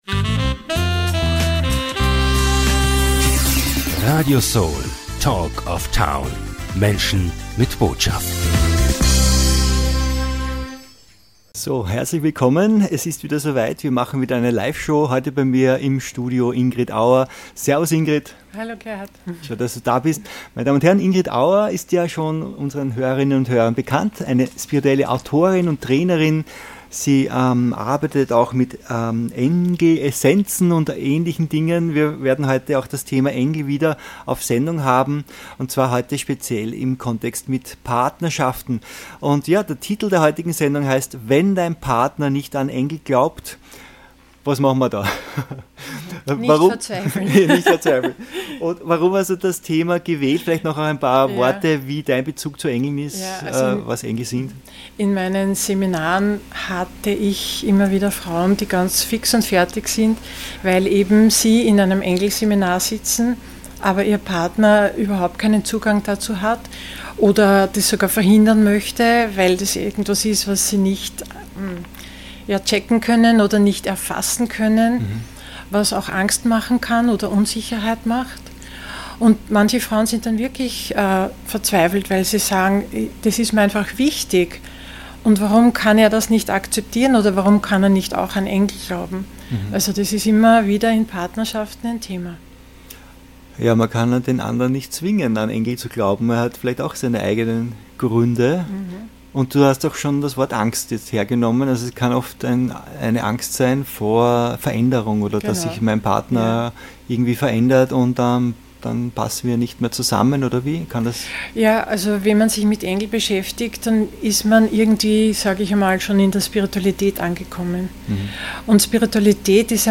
Freuen Sie sich auf dieses inspirierendem Interview